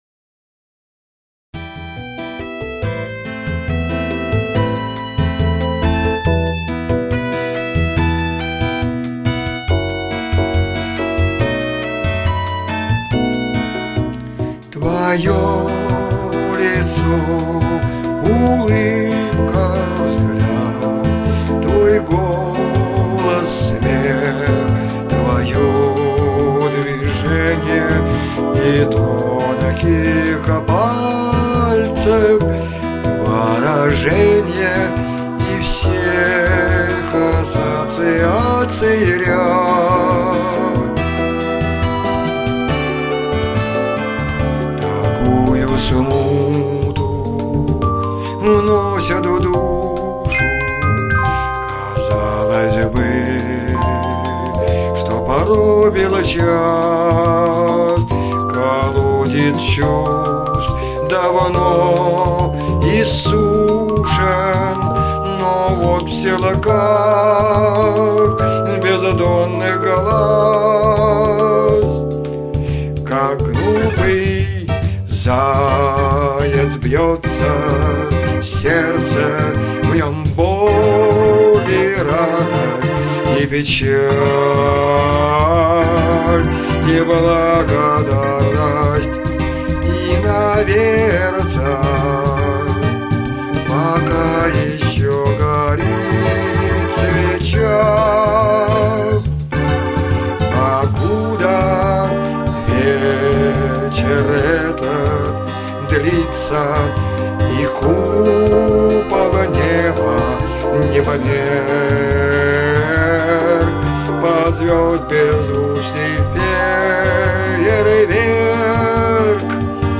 Лирическая музыка